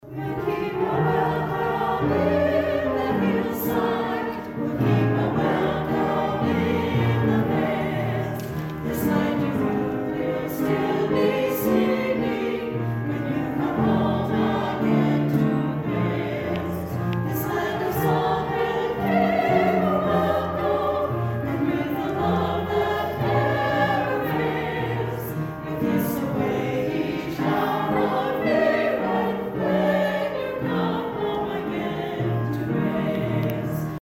Emporia Presbyterian Church West Campus hosts 137th annual St. David’s Day concert Sunday
a choir made up of community members
The selections for Sunday’s concert included the singing of both the American and Welsh national anthems and various hymns, including the traditional Welsh welcome song “We’ll Keep A Welcome Hill in the Hillside.”